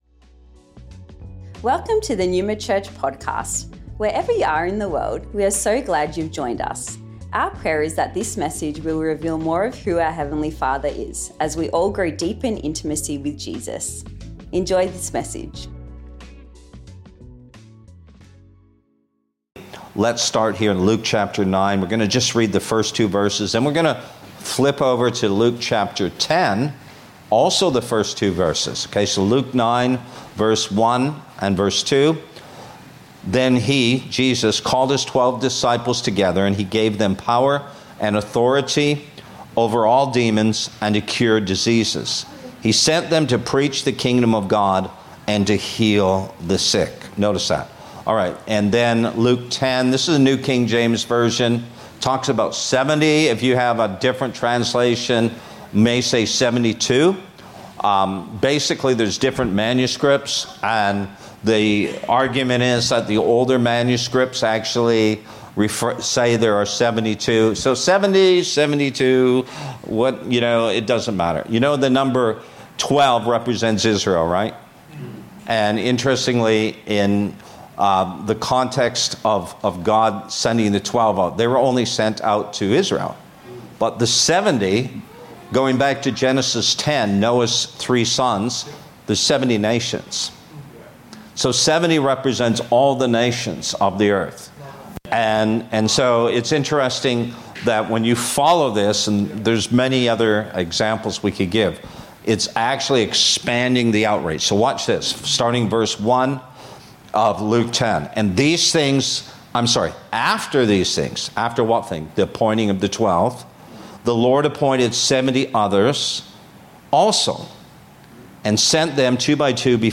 In this sermon